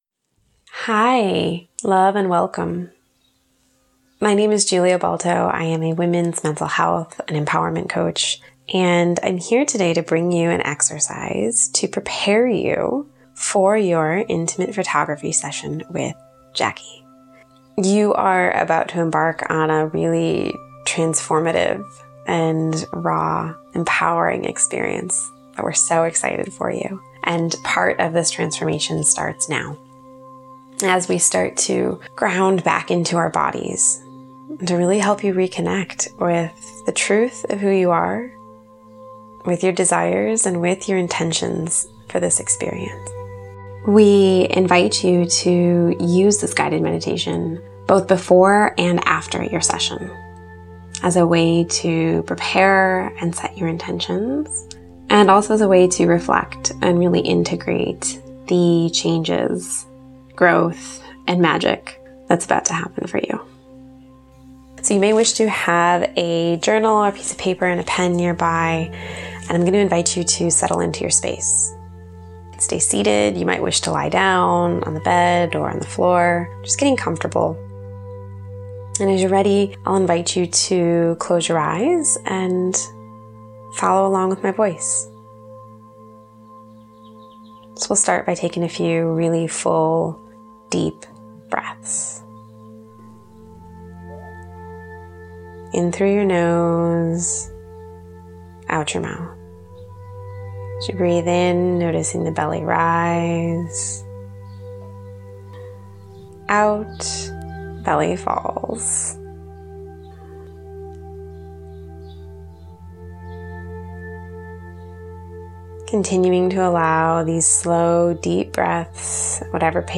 Intimate Photography Guided Meditation
IntimatePhotography_GuidedMeditation.mp3